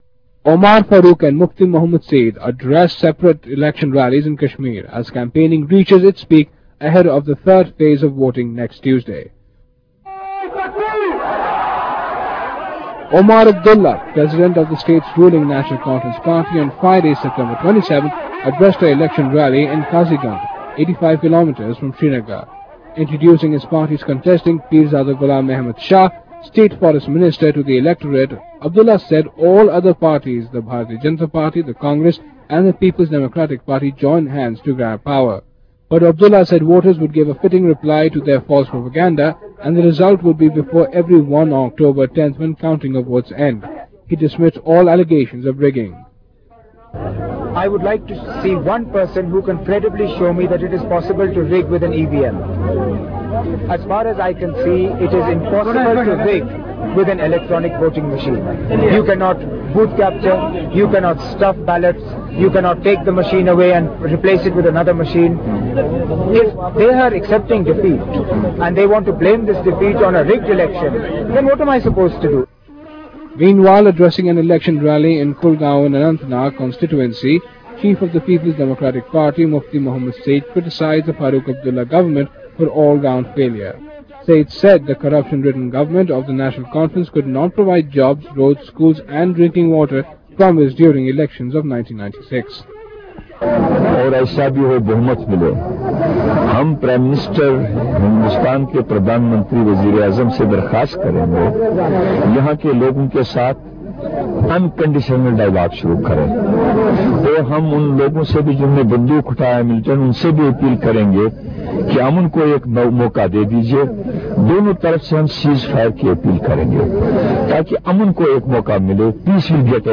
Omar, Farooq and Mufti Mohammad Syeed address separate election rallies in Kashmir as campaigning reaches its peak ahead of third phase of voting next Tuesday.